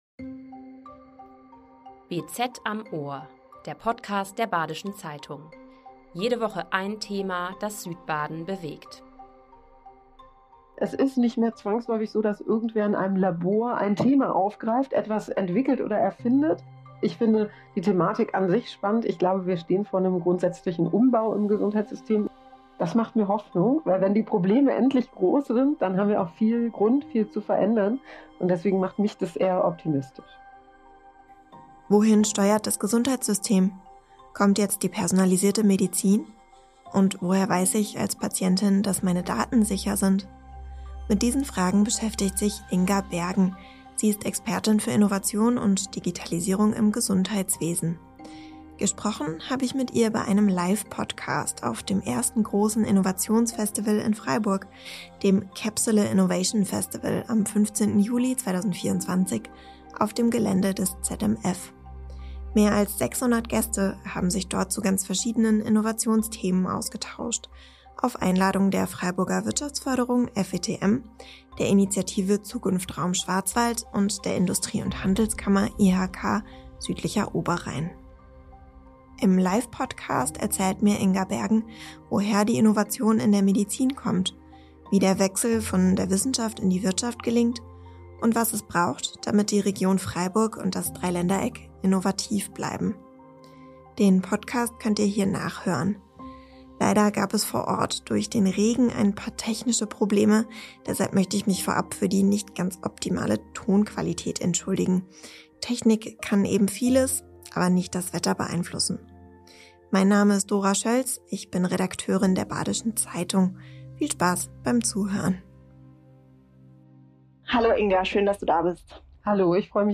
Leider gab es vor Ort durch den Regen ein paar technische Probleme – deshalb möchten wir uns für die nicht optimale Tonqualität entschuldigen.